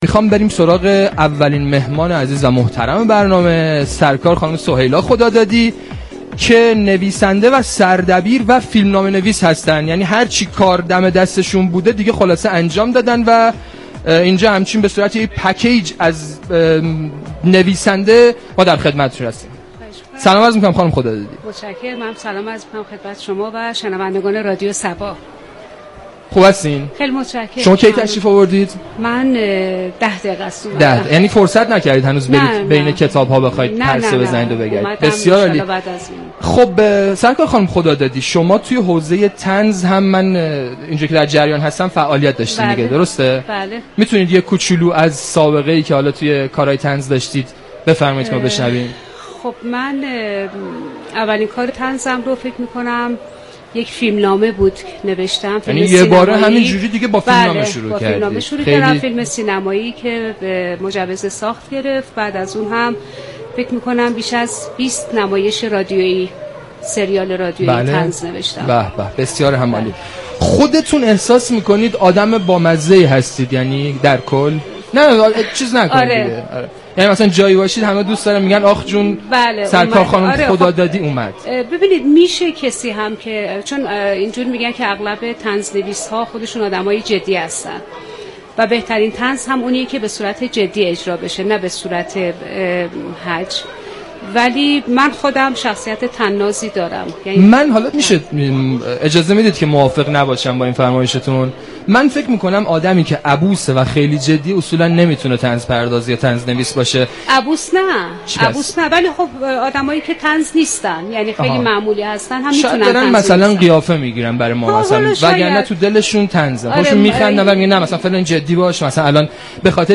رادیو صبا در نمایشگاه كتاب تهران میزبان نویسندگان و طنز پردازان خوب كشورمان می شود و با آنها گفتگو صمیمی دارد.
این ویژه ‌برنامه در فضایی شاد در بستر شوخی و مطایبه به گفتگو با مسئولین و تصمیم گیرندگان صنعت نشر مولفین و مترجمین و مردم حاضر در نمایشگاه می پردازد.